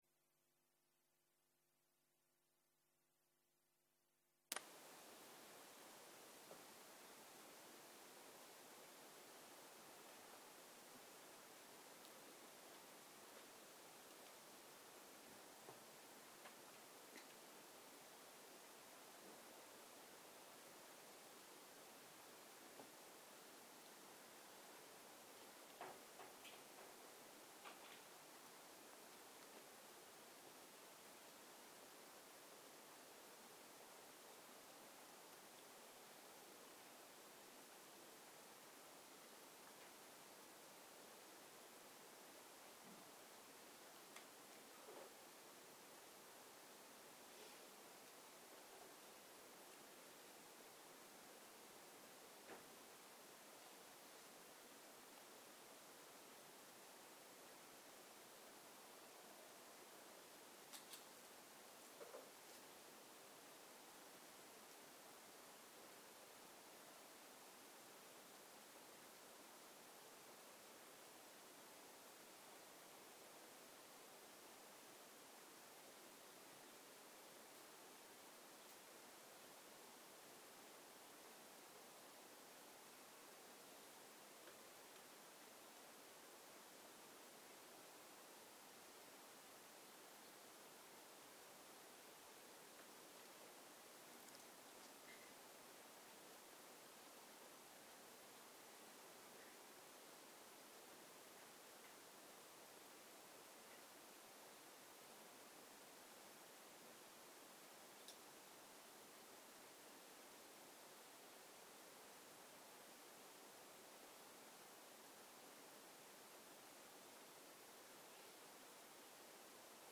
בוקר - הנחיות מדיטציה + הנחיות למדיטציה בהליכה - טיפוח אווירה מטיבה לתרגול 0:00 0:00 סוג ההקלטה: סוג ההקלטה: שיחת הנחיות למדיטציה שפת ההקלטה: שפת ההקלטה: עברית